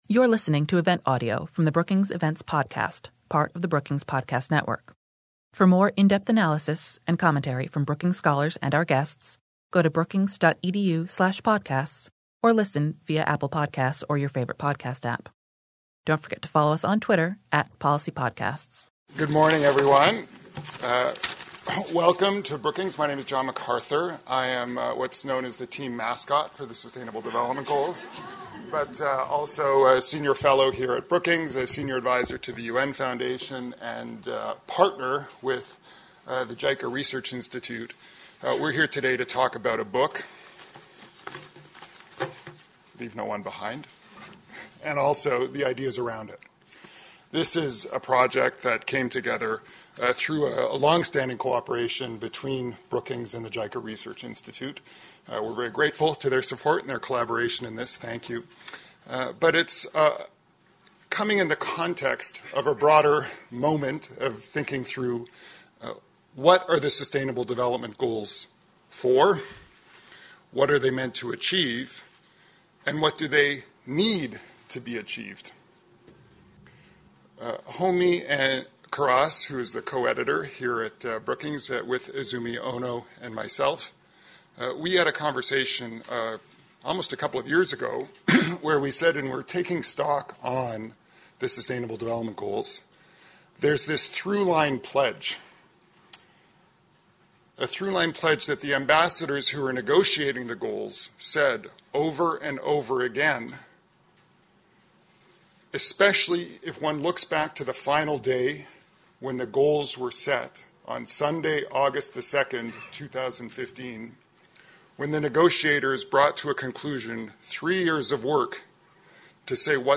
A moderated panel of experts followed. Following the discussion, the panel answered questions from the audience.